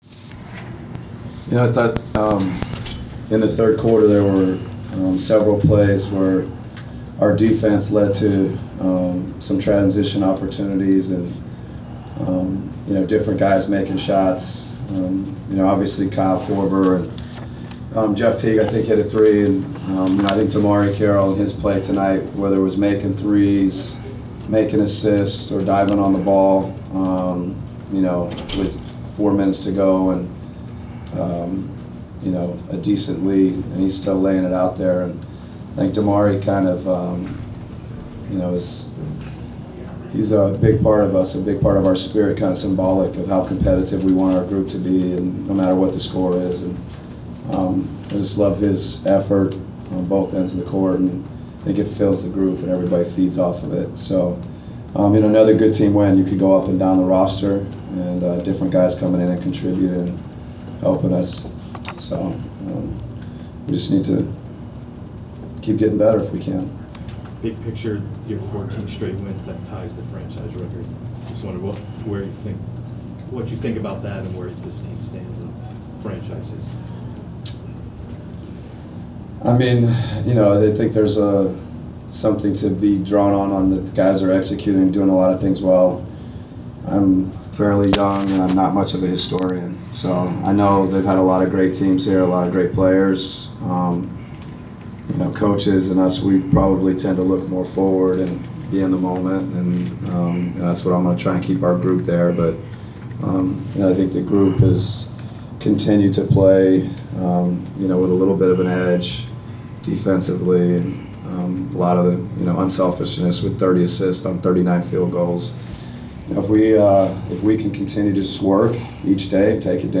Inside the Inquirer: Postgame presser with Atlanta Hawks’ head coach Mike Budenholzer (1/21/15)
We attended the postgame press conference of Atlanta Hawks’ head coach Mike Budenholzer following his team’s 110-91 home victory over the Indiana Pacers on Jan. 21.